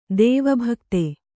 ♪ dēva bhakti